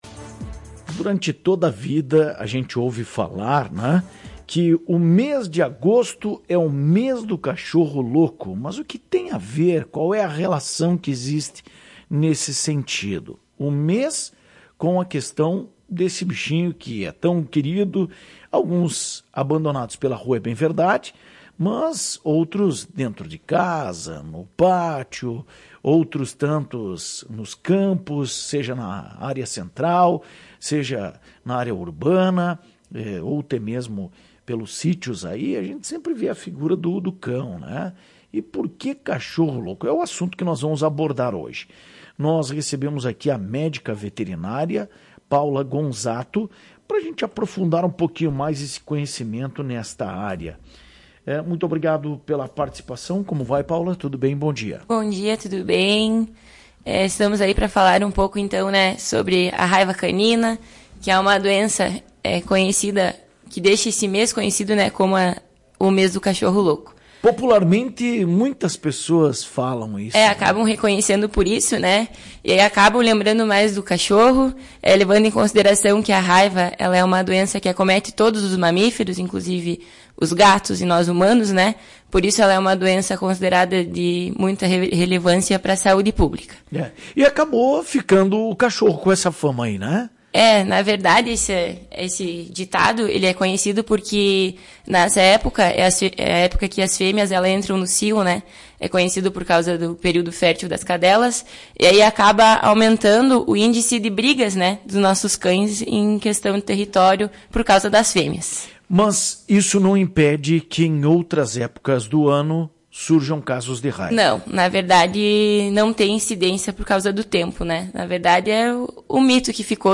Profissional concedeu entrevista ao Temática